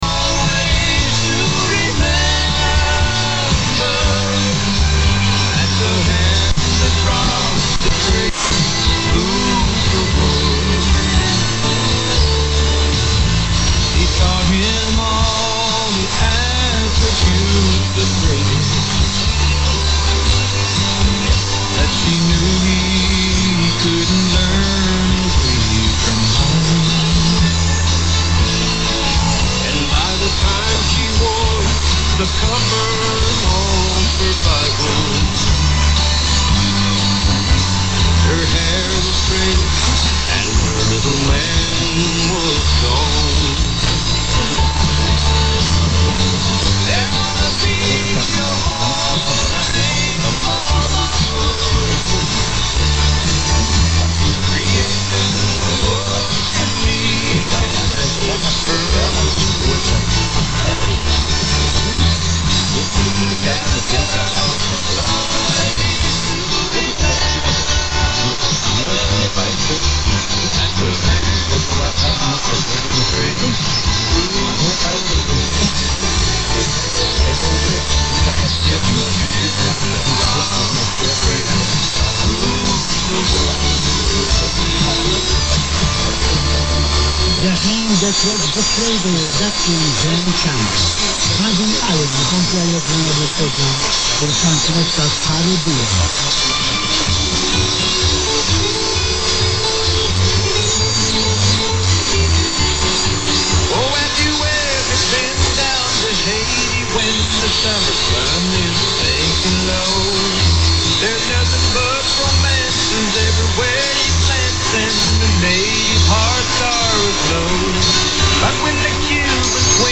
felvételemen 3:55-nél hallható azonosító...sajnos az 1kW-os adót az olaszok, spanyolok (ill. TWR) "általában" elnyomják, így nálunk ritkán vehető